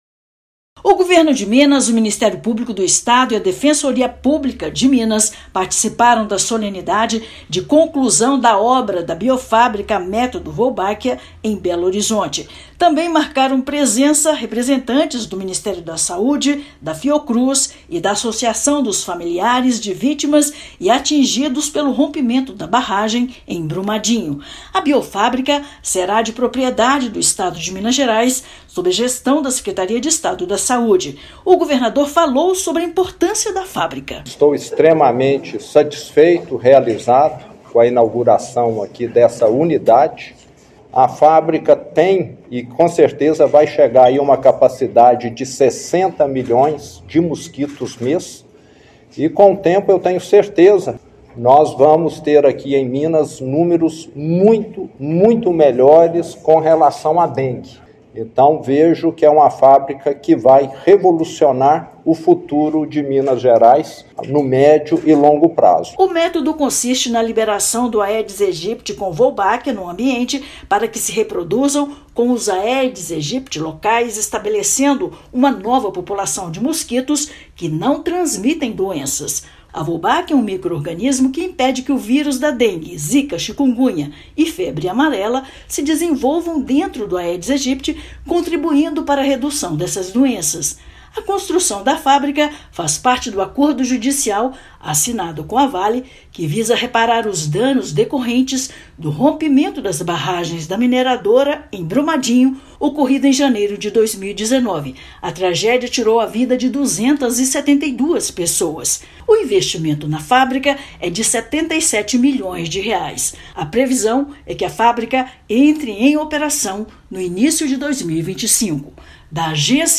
Com investimento de mais de R$ 77 milhões, projeto vai viabilizar a utilização de tecnologia inovadora e autossustentável, que reduz a transmissão de doenças causadas pelo Aedes. Ouça matéria de rádio.